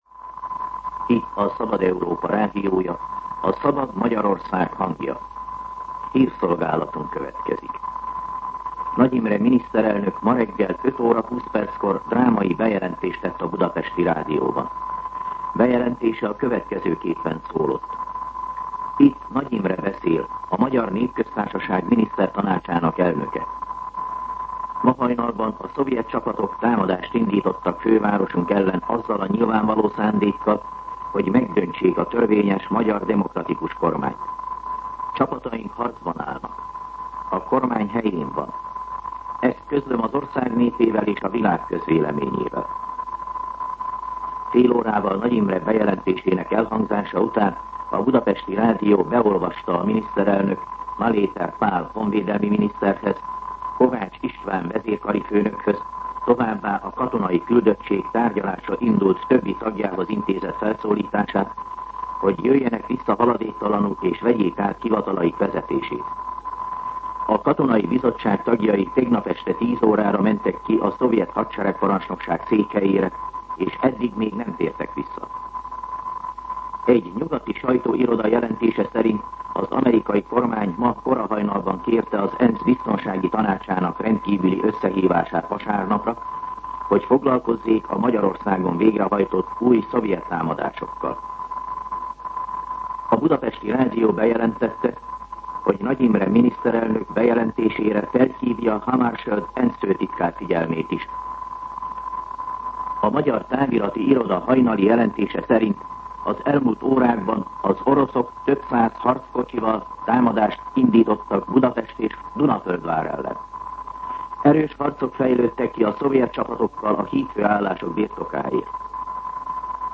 MűsorkategóriaHírszolgálat